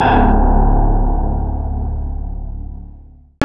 Index of /90_sSampleCDs/Roland L-CD701/BS _Synth Bass 1/BS _Wave Bass
BS  PPG BASS.wav